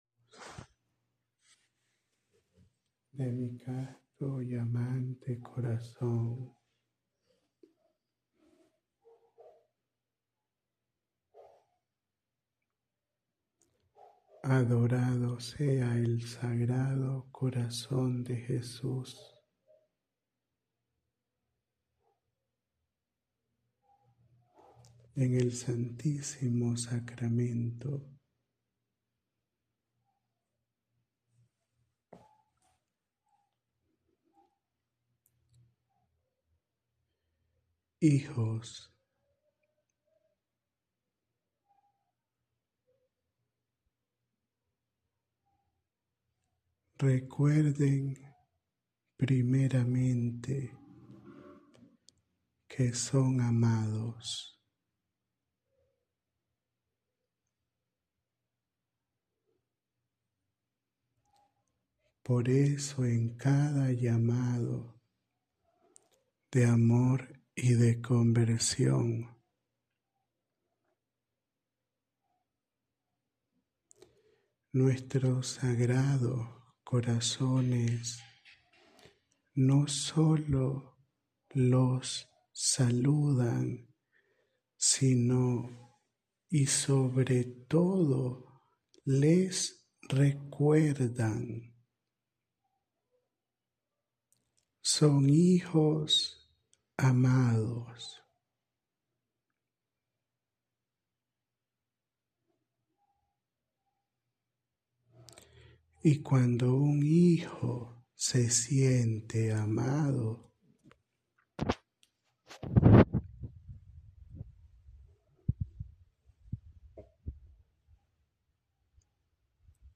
Áudio da Mensagem